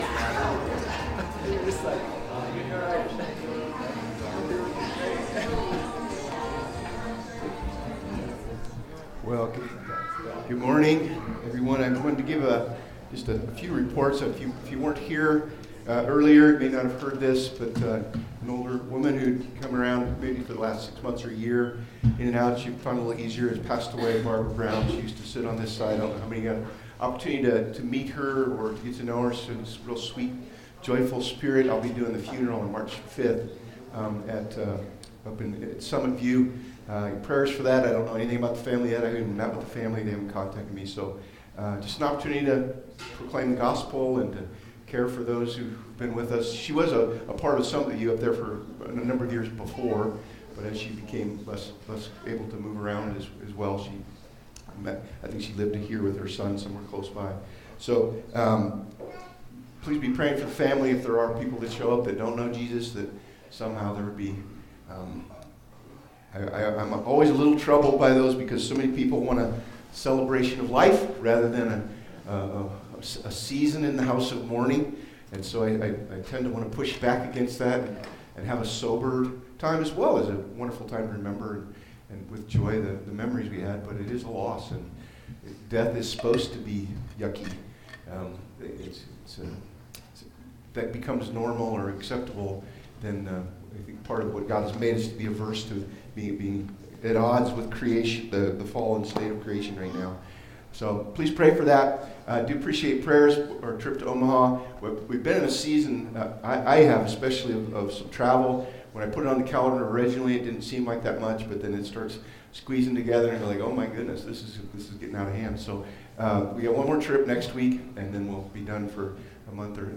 Service Type: Sunday Topics: Baptism